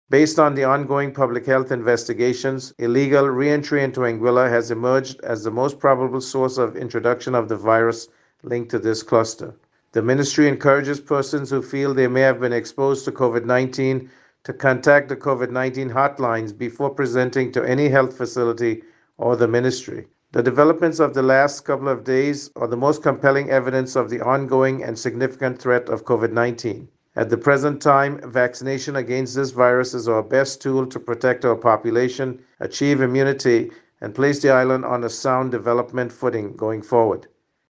Premier and Minister of Health in Anguilla, Dr. Ellis Webster gave this update about the COVID-19 situation:
Premier Webster also provided this additional piece of information: